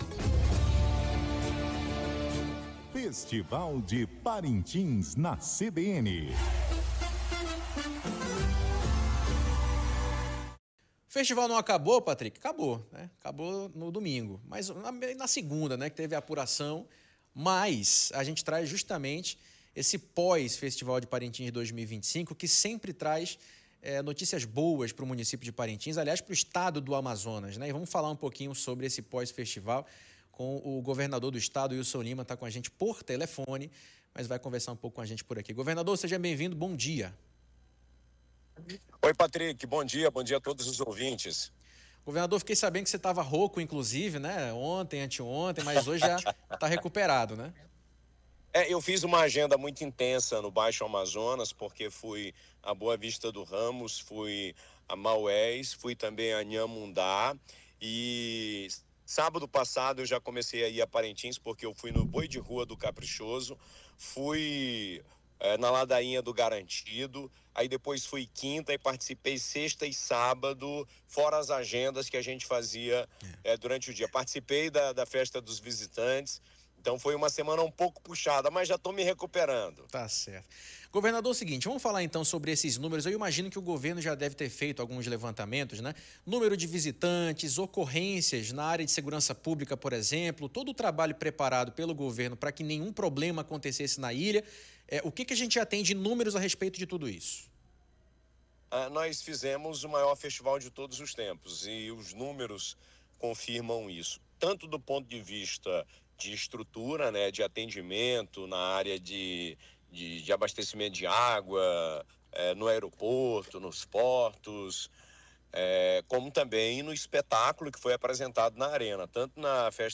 Declaração ocorreu durante entrevista ao CBN Jornal da Manhã, nesta quarta-feira (2). O governador do Amazonas Wilson Lima deu detalhes sobre o contrato para a reforma do bumbódromo.